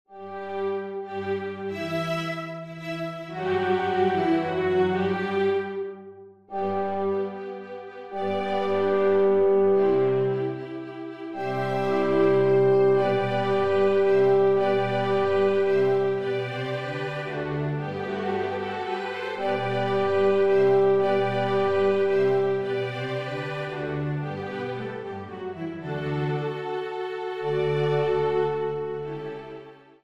Singspiel
Orchester-Sound